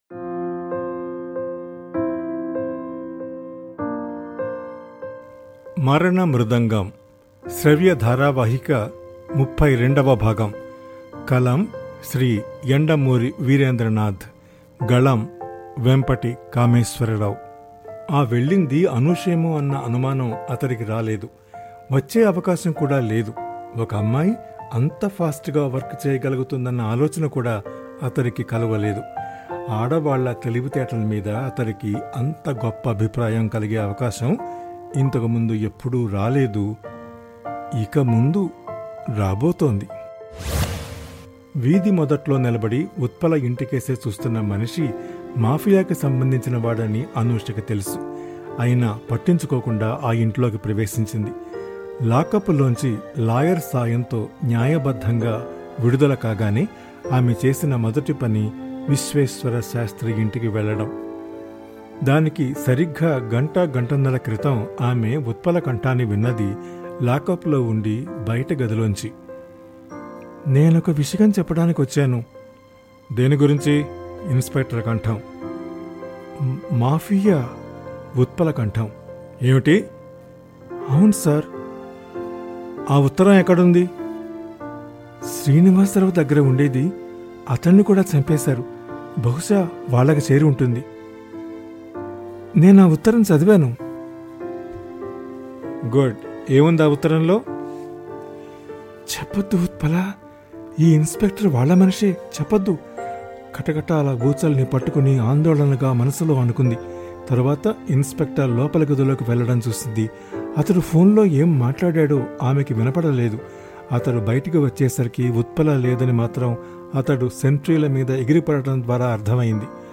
Yandamoori Veerendranath - Marana Mrudangam (Telugu audio book) – Podcast